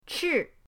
chi4.mp3